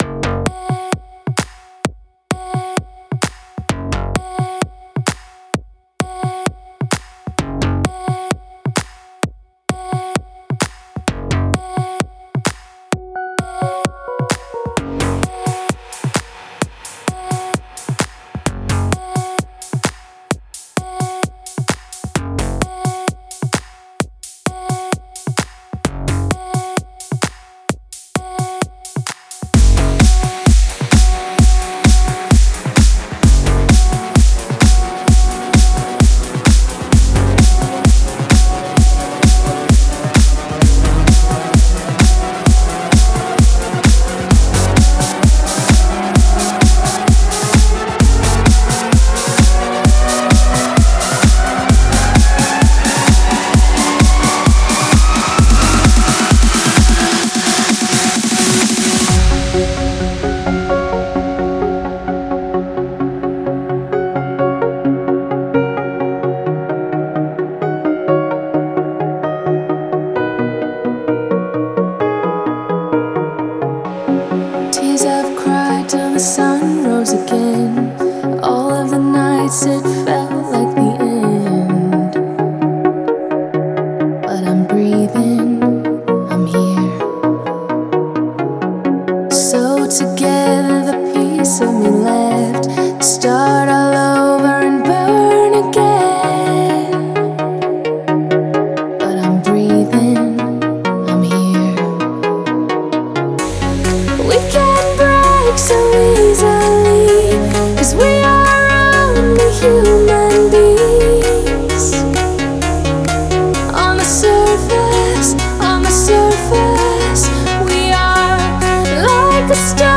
Стиль: Vocal Trance